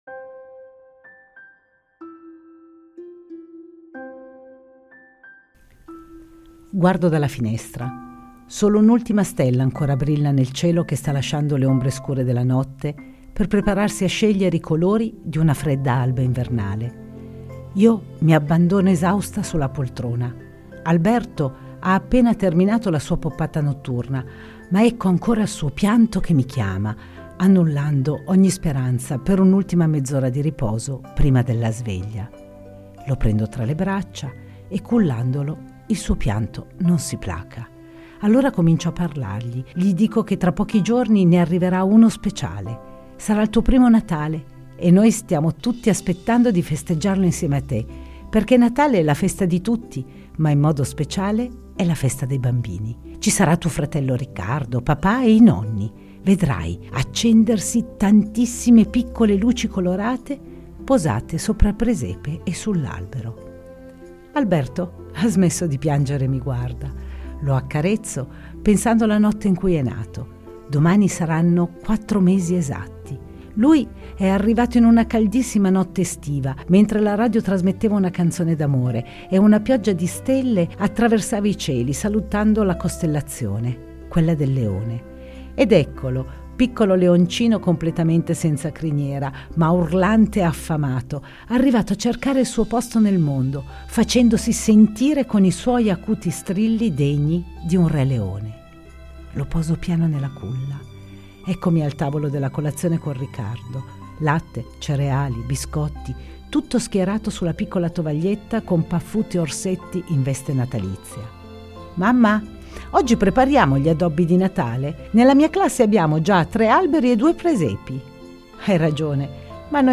storia vera